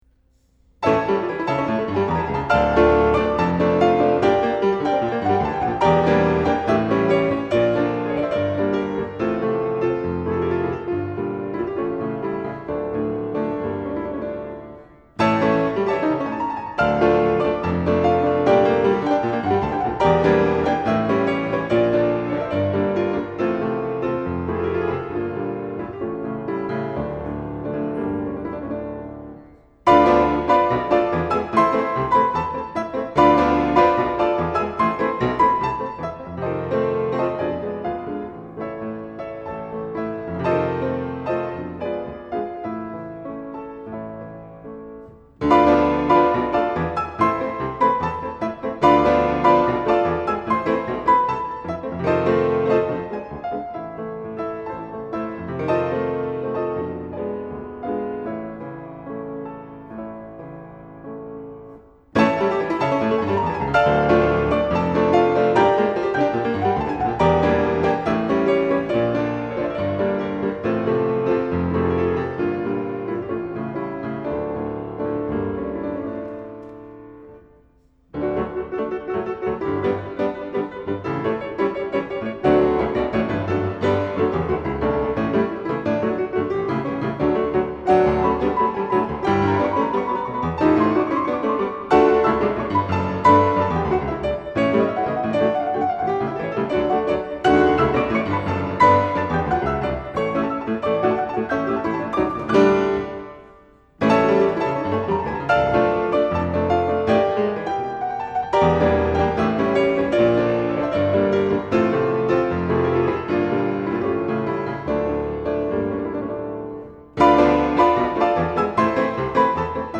Recitals - July 25, 2008
Six Players - Brahms Hungarian Dances.mp3